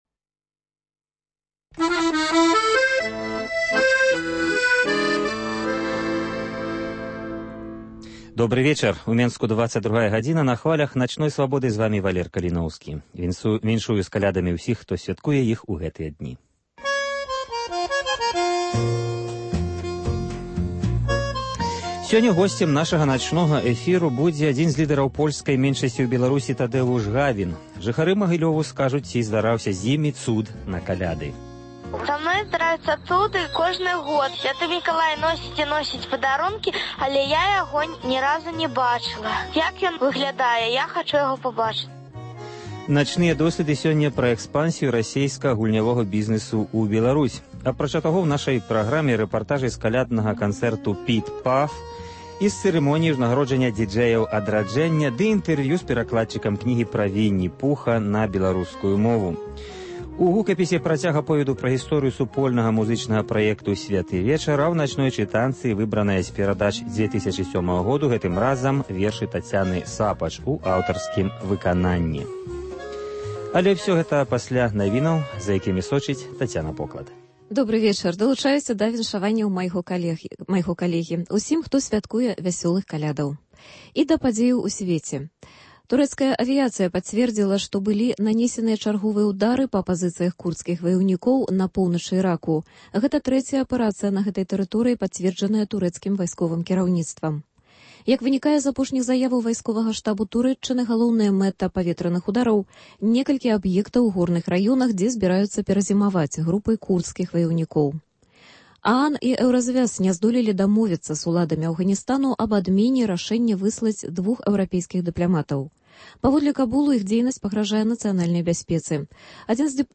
* Апытаньне: ці здараўся зь вамі цуд на Каляды? * "Начныя досьледы" – пра экспансію расейскага гульнявога бізнэсу ў Беларусі * Рэпартаж з каляднага канцэрту гурту “Піт-Паф” * Прэйскурант на паслугі Дзеда Мароза ў Менску * Чаму ля менскіх ЗАГСаў цяпер вялікія чэргі?